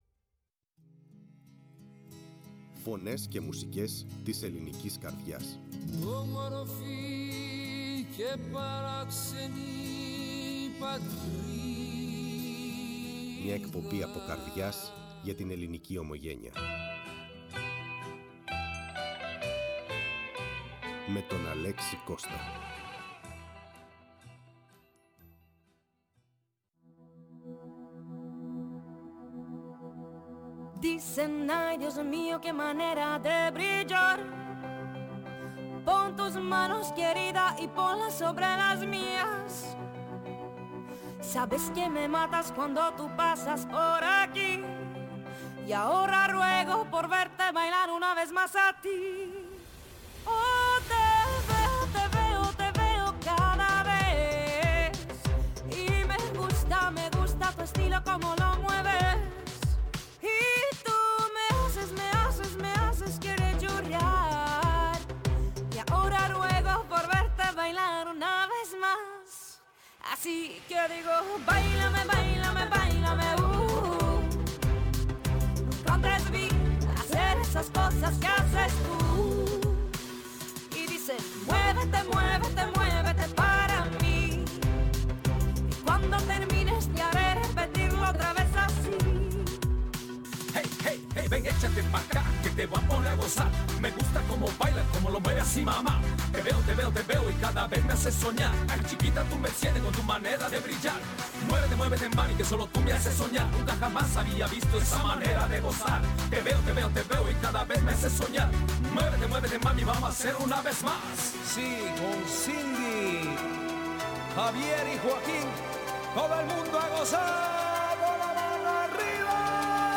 φιλοξένησε ζωντανά στο στούντιο της Φωνής της Ελλάδας